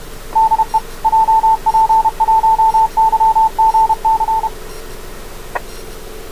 emojiドライブレコーダーのノイズが混入してしまいましたが、“DE JP1YGC”のCWの跡が浮き出ています
emoji1292.56MHz JP1YGCのID（2013年12月20日、9時11分、千葉県茂原市法目付近で録音）
オーディオケーブルなどを持参していなかった為、ドライブレコーダーの動画データから音声だけ吸い出ししたので、ノイズが混入しています。emoji